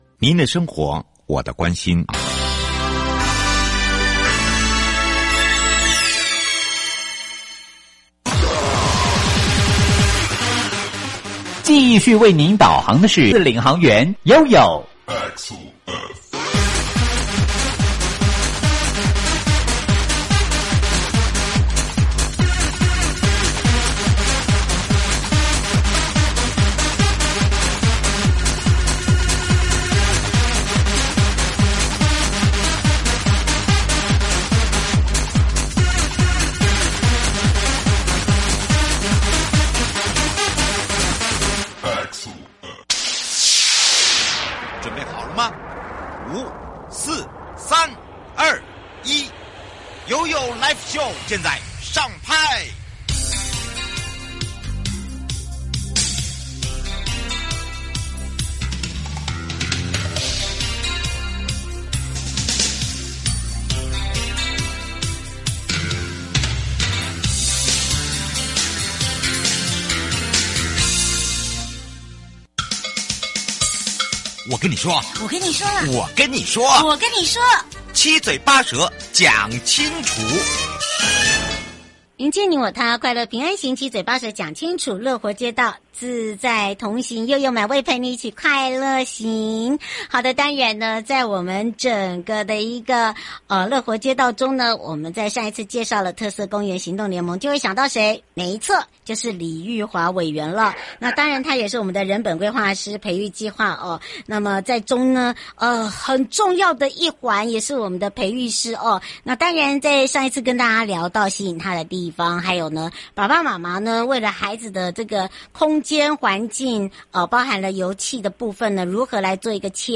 受訪者： 營建你我他 快樂平安行~七嘴八舌講清楚~樂活街道自在同行!(二) 聽說「兒童95公分視角翻轉鏡」，從